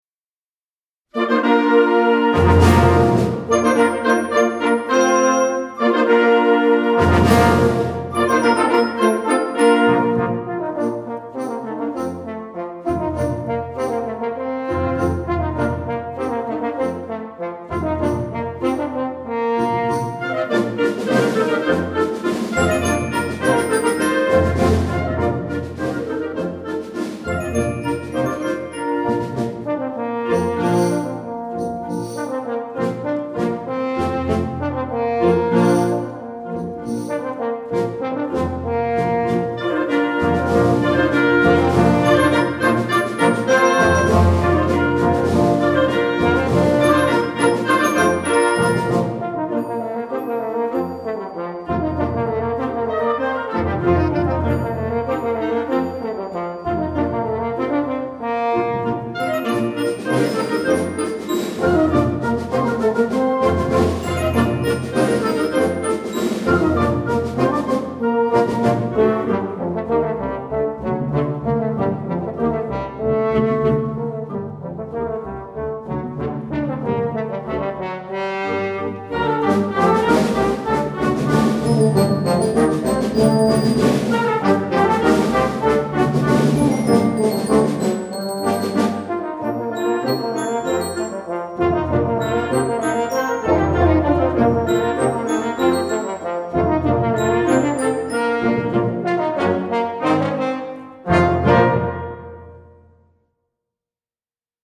Gattung: Suite für Horn und Blasorchester
Besetzung: Blasorchester